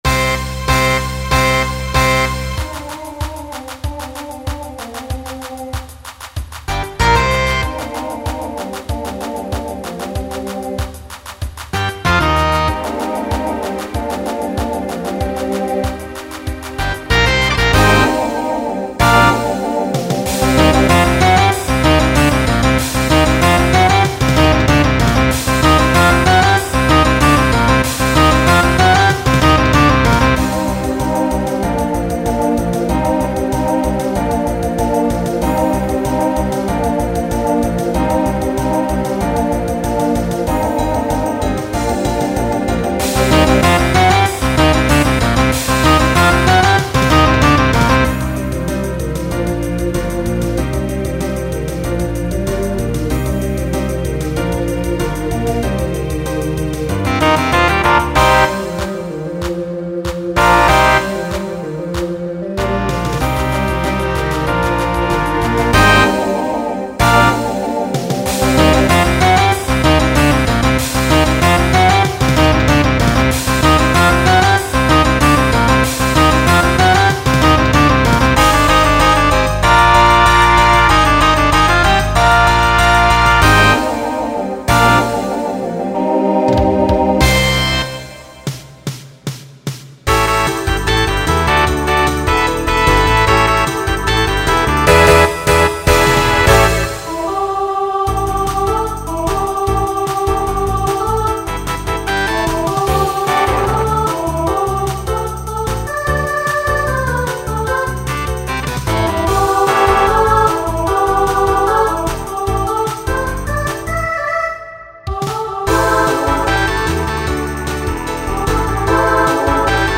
(TTB)
(mostly SSA with some SATB at the end).
Genre Pop/Dance
Voicing Mixed